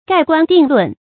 蓋棺定論 注音： ㄍㄞˋ ㄍㄨㄢ ㄉㄧㄥˋ ㄌㄨㄣˋ 讀音讀法： 意思解釋： 指一個人的是非功過到死后才能做出結論。